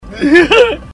Laugh 16